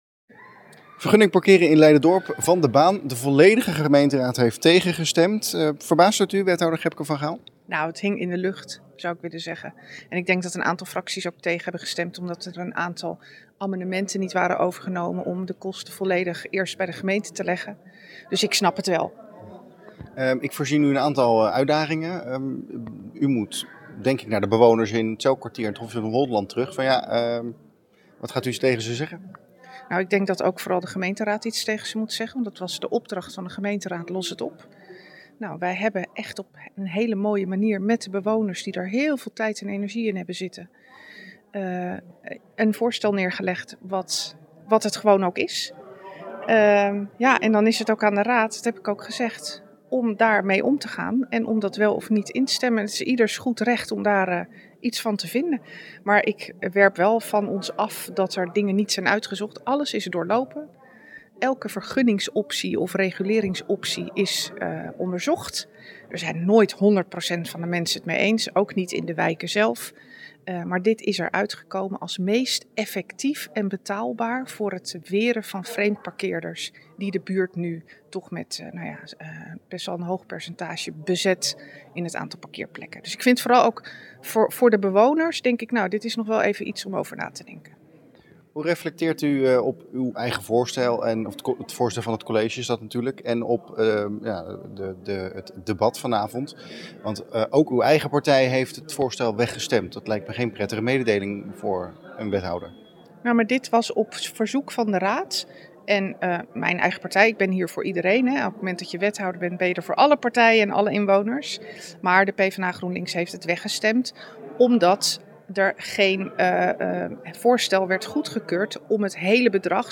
Wethouder Gebke van Gaal na afloop van de vergadering.